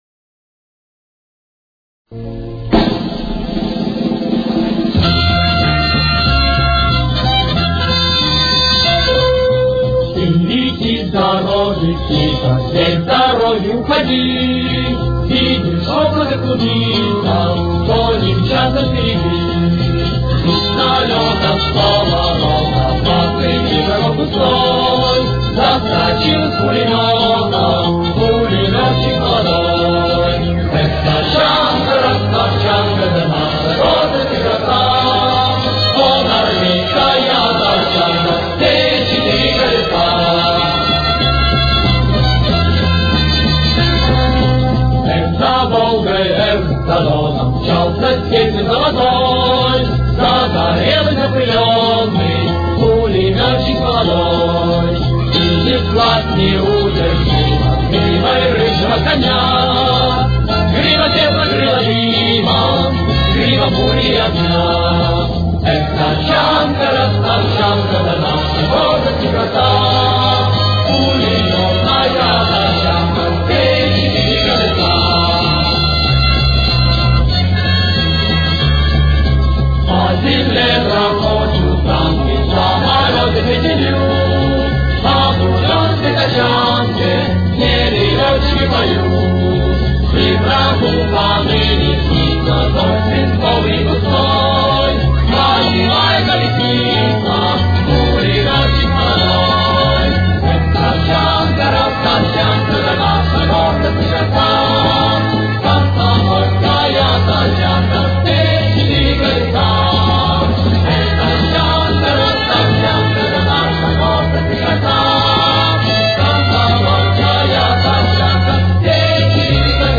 с очень низким качеством (16 – 32 кБит/с)
Тональность: Ми минор. Темп: 190.